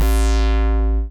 death2.wav